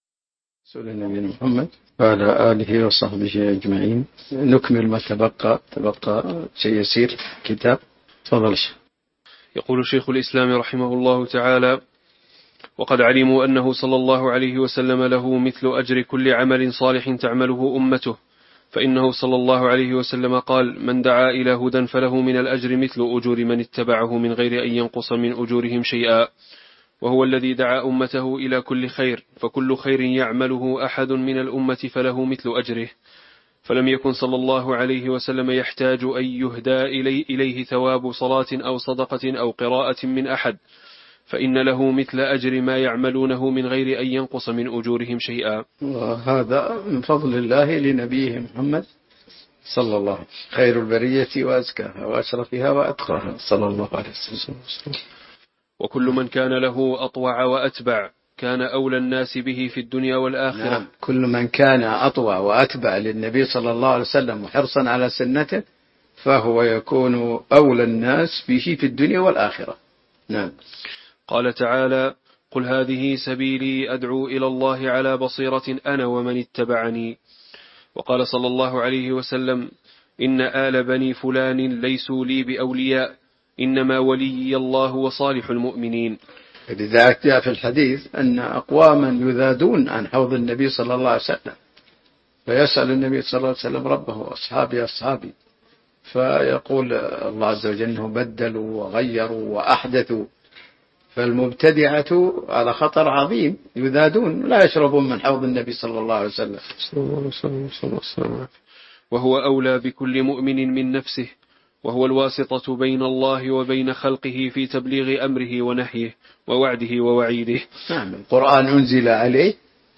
تاريخ النشر ٣ ذو الحجة ١٤٤٦ هـ المكان: المسجد النبوي الشيخ